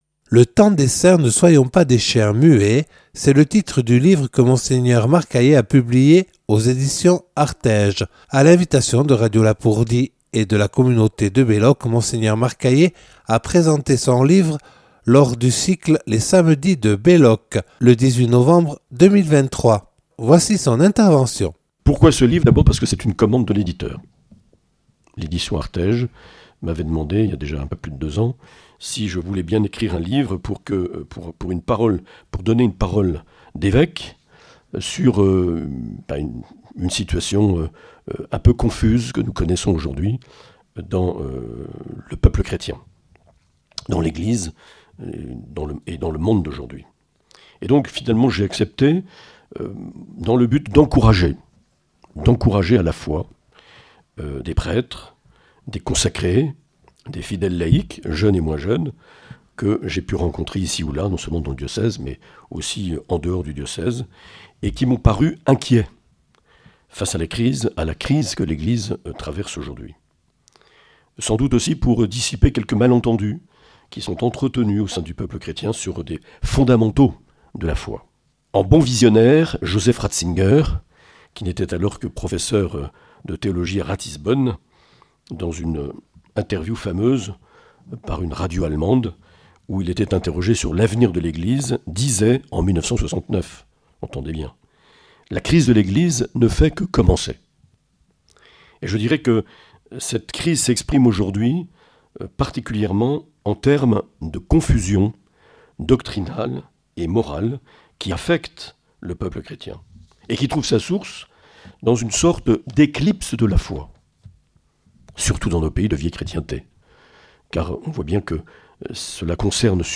Mgr Marc Aillet a présenté son livre à l'abbaye de Belloc le 18 novembre 2023.